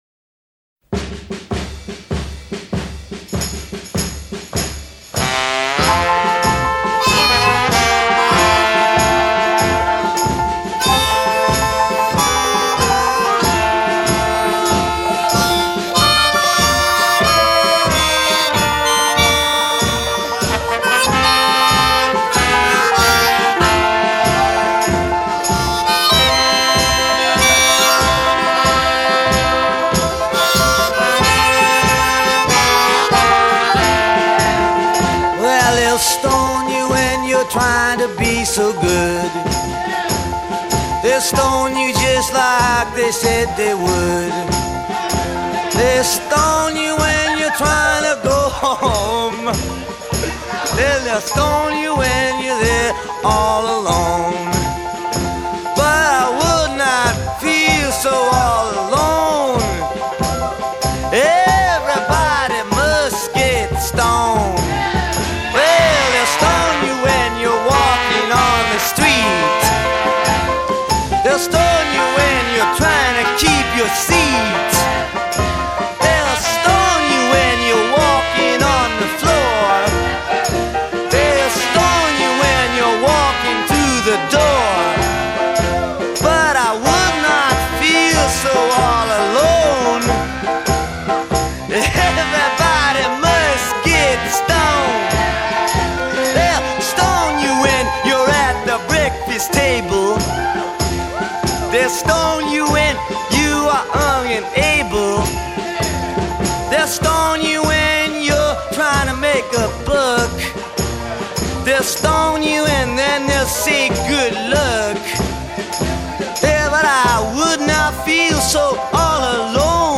Folk Rock, Rock